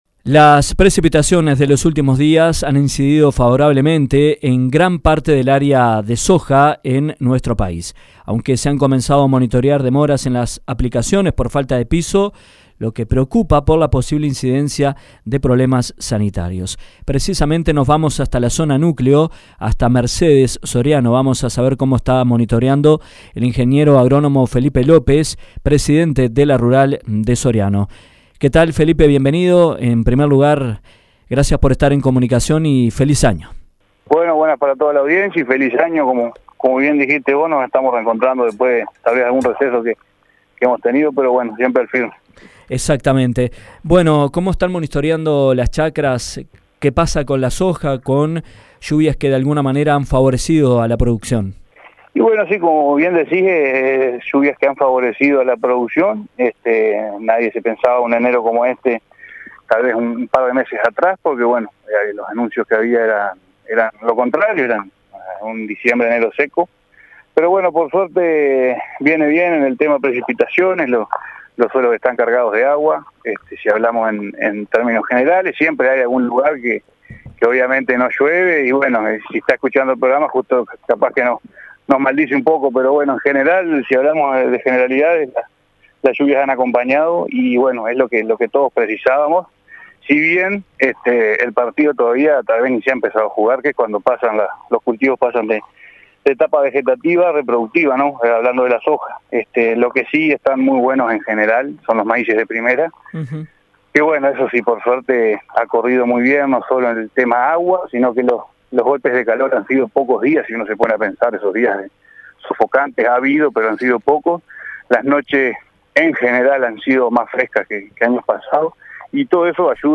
Las precipitaciones de los últimos días han favorecido en gran parte el área sojera del país, según operadores consultados, aunque se comenzaron a monitorear demoras en las aplicaciones por falta de piso, lo que preocupa por la posible incidencia en la sanidad del cultivo. En entrevista